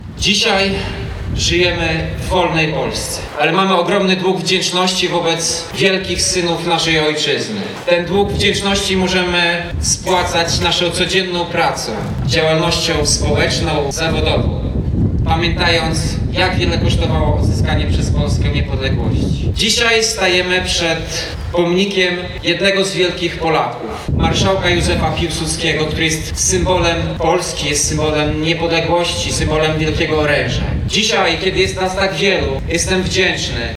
Bicie dzwonów w południe, Msza Święta pod przewodnictwem biskupa Diecezji Ełckiej ks. Jerzego Mazura, przemarsz z katedry przed ełcki Ratusz i odsłonięcie tam pomnika Marszałka Józefa Piłsudskiego – tak przebiegała w Ełku oficjalna część uroczystości związanych z świętowaniem 100 rocznicy odzyskania przez Polskę Niepodległości.
– Dzisiaj Polska to nie tylko granice, to nie tylko skrawek ziemi, ale również tożsamość, naród, także my, ełczanie, mówił prezydent miasta Tomasz Andrukiewicz.